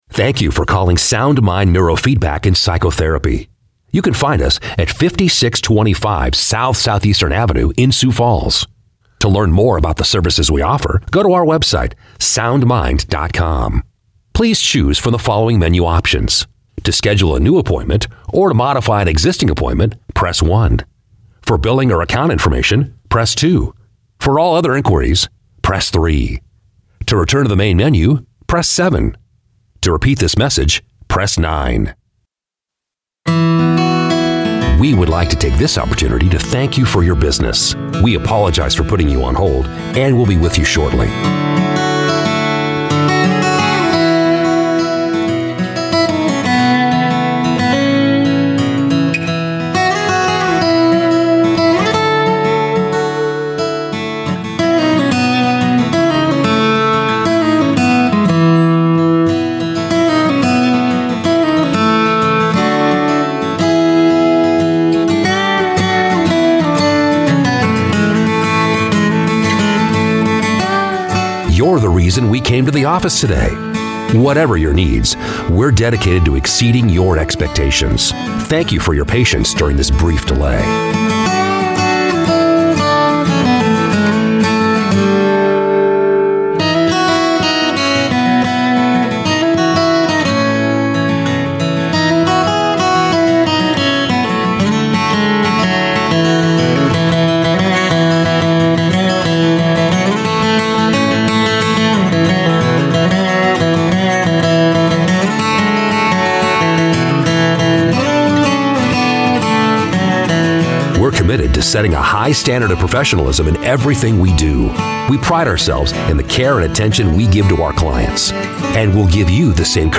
Male
Adult (30-50), Older Sound (50+)
I deliver a warm, friendly, relatable tone with clarity and articulation. I also possess a versatile and dynamic range that is energetic and enthusiastic, as well as authoritative and confident.
Phone Greetings / On Hold
Phone Menu/Messaging
0512Demo_-_IVR_Phone_System.mp3